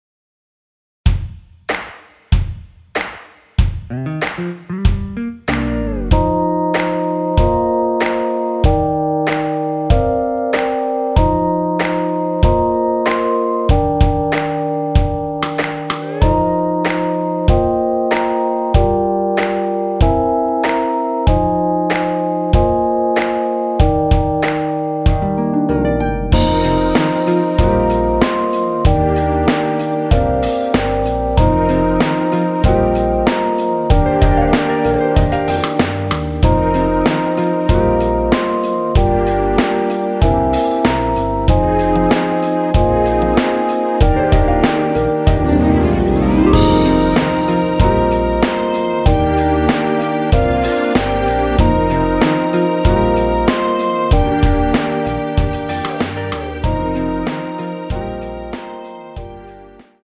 엔딩이 페이드 아웃이라 엔딩을 만들어 놓았습니다.(원키 미리듣기 참조)
Eb
앞부분30초, 뒷부분30초씩 편집해서 올려 드리고 있습니다.